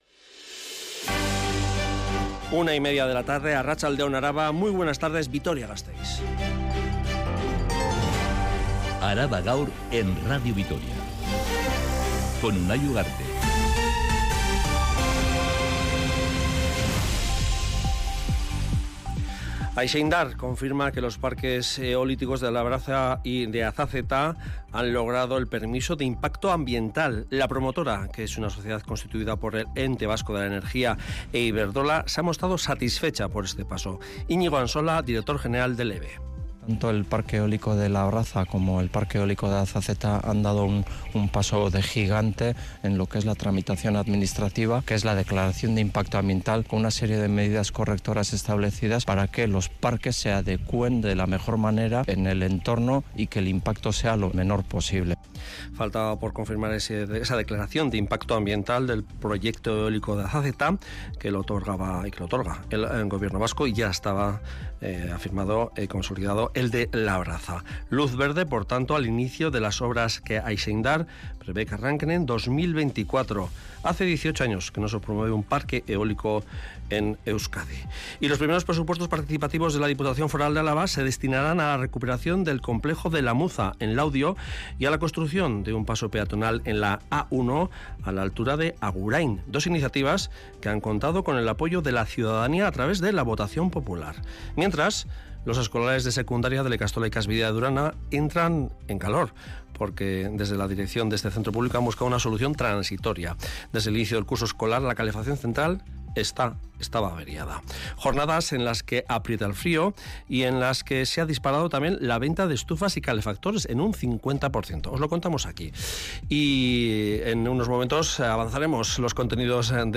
Radio Vitoria ARABA_GAUR_13H Araba Gaur (Mediodía) (25/01/2023) Publicado: 25/01/2023 14:59 (UTC+1) Última actualización: 25/01/2023 14:59 (UTC+1) Toda la información de Álava y del mundo. Este informativo que dedica especial atención a los temas más candentes de la actualidad en el territorio de Álava, detalla todos los acontecimientos que han sido noticia a lo largo de la mañana.